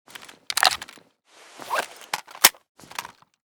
pp2000_reload.ogg